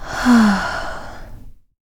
SIGH 6.wav